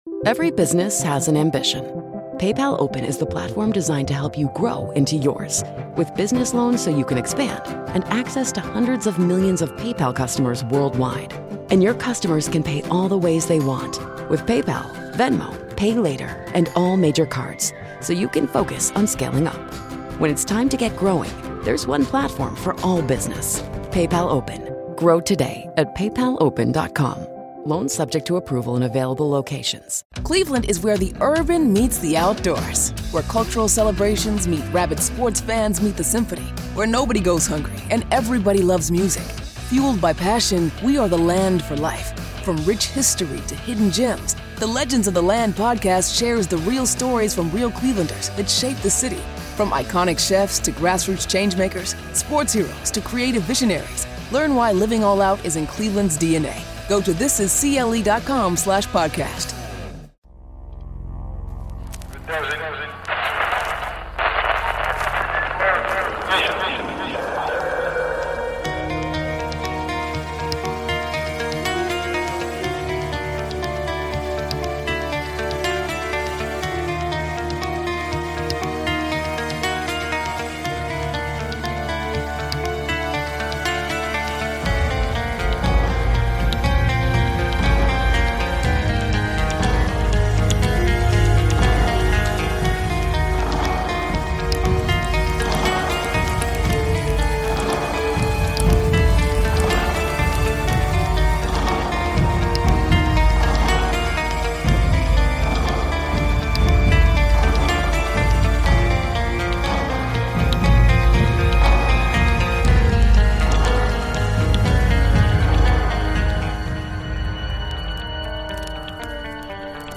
Tonight I play strange calls from all over North America. Aliens, Mothman, ghosts and much more.